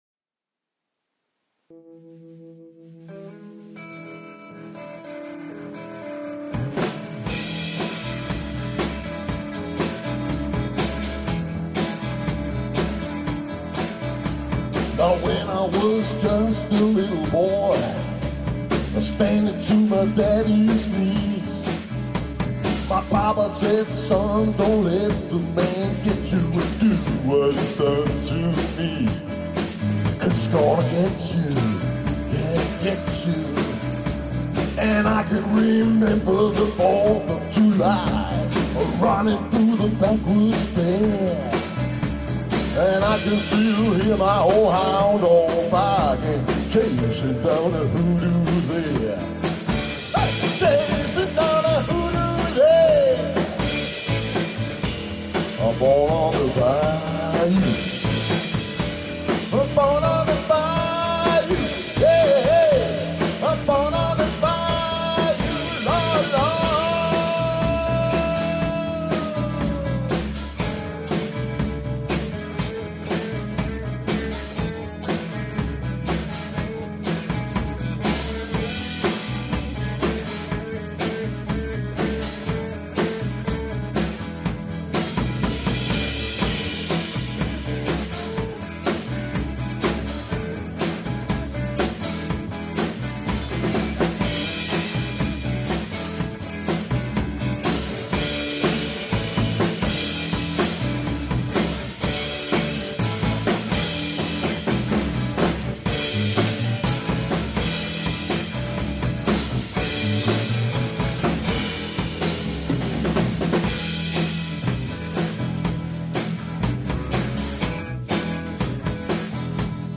LIVE
VOCAL
LEAD GUITAR
BASS
DRUMS
RECORDED BY ZOOM MOBILSTUDIO